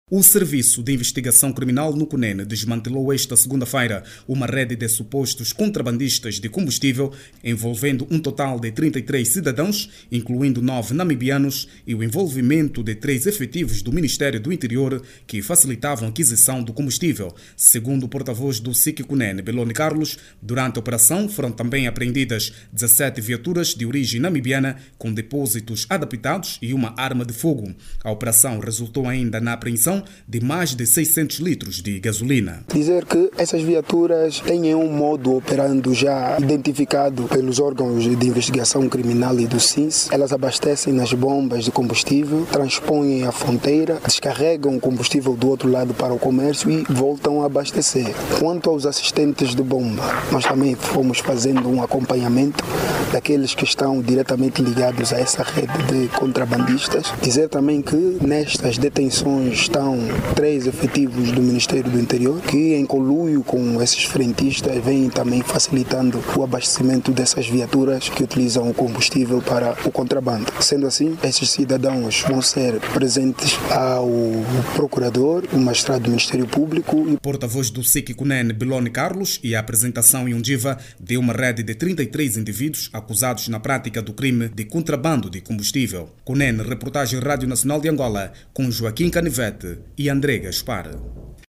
A operação dos Serviços de Investigação Criminal incidiu desta vez na Província do Cunene onde prendeu três efectivos do Ministério do Interior e frentistas. No total o SIC, deteve 33 cidadãos de nacionalidade angolana e namibiana. Ouça no áudio abaixo toda informação com a reportagem